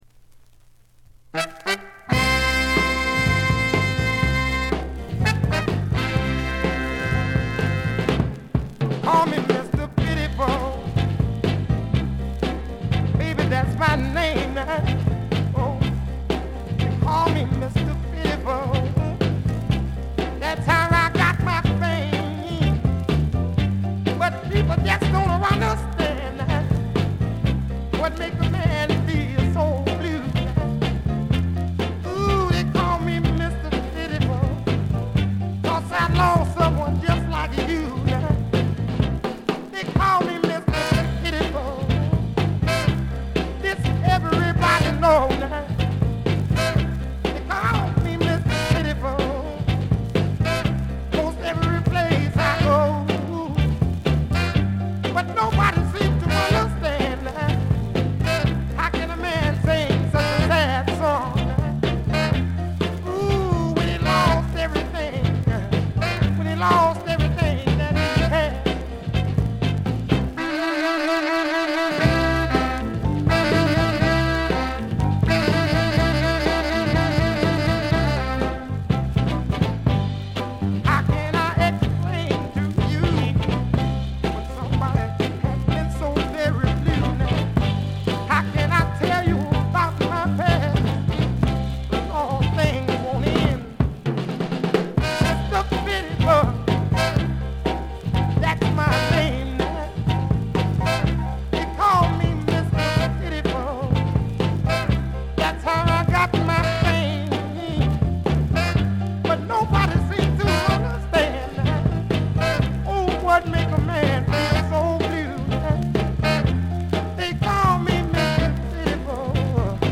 静音部でのバックグラウンドノイズ程度。鑑賞を妨げるようなノイズはありません。
試聴曲は現品からの取り込み音源です。